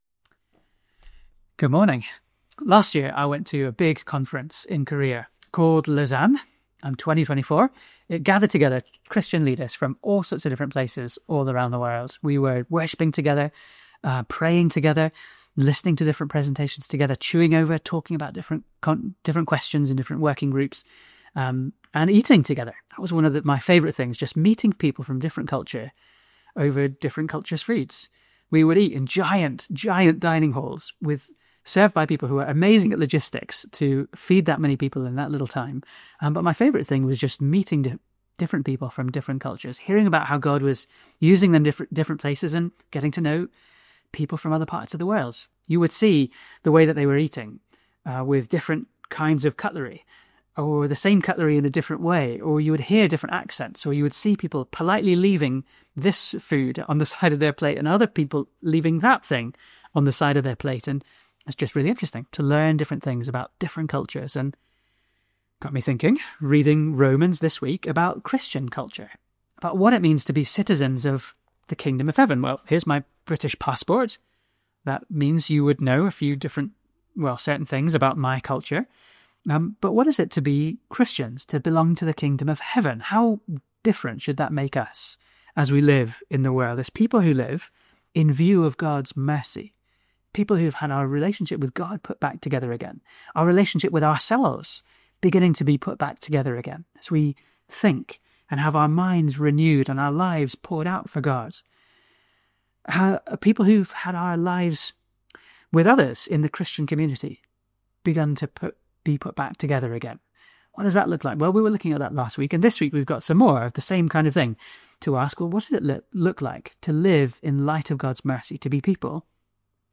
A blend of the latest and classic sermons preached at Ammanford Evangelical Church.